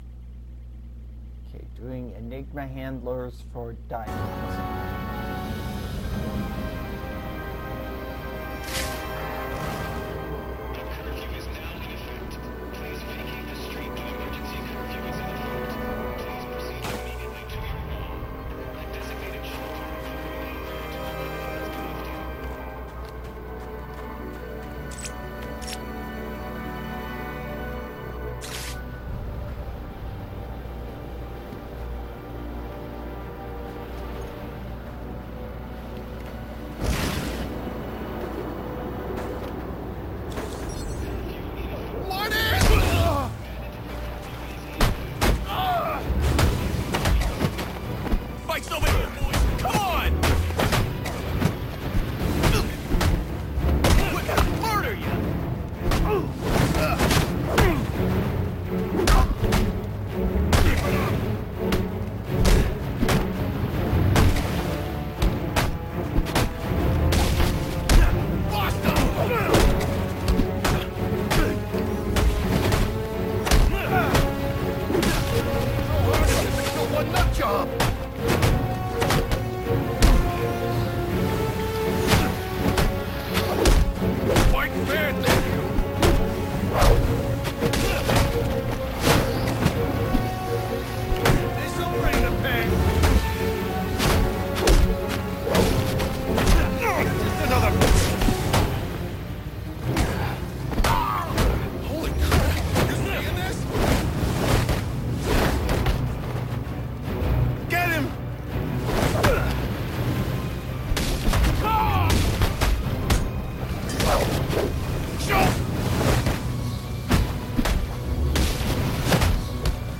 I play Batman Arkham Origins with commentary